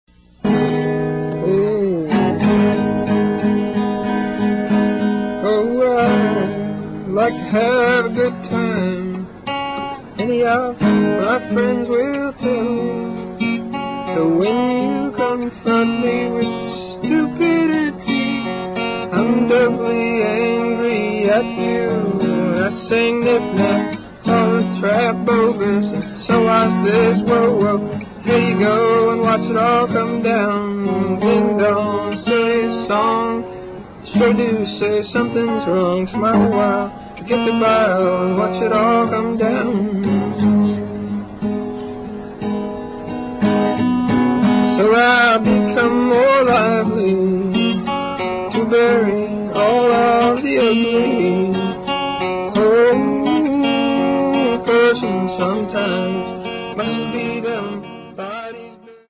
live in Haiti